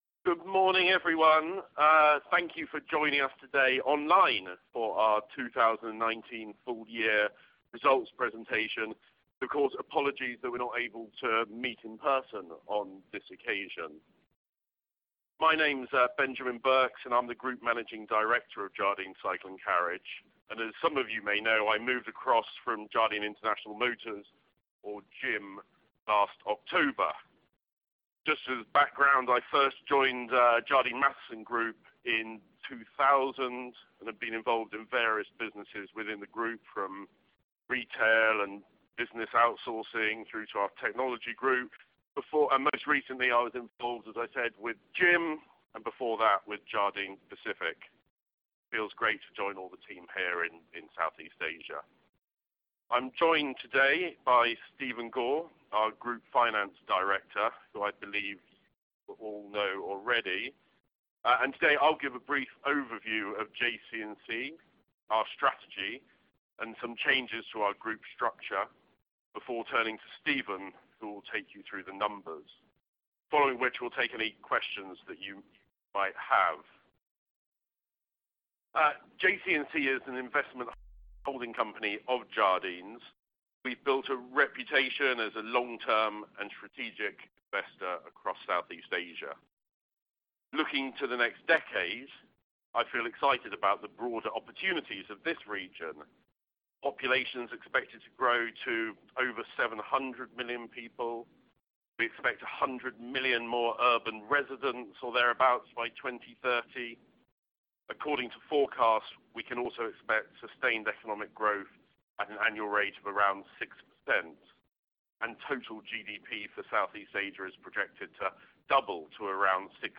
JC&C 2019 Full Results Presentation to Analysts
FY2019 Analyst Presentation without QandA.mp3